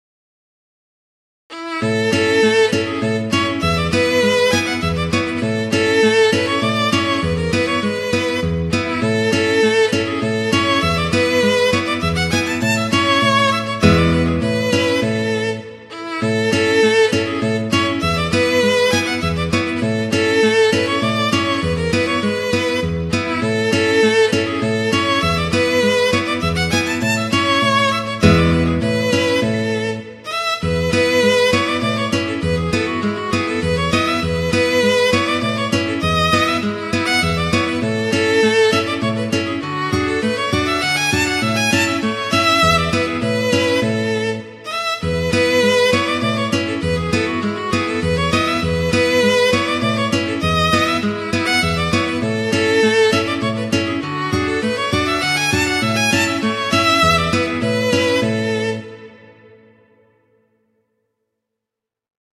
violin and guitar
classical
02Polonaise_Andantino.mp3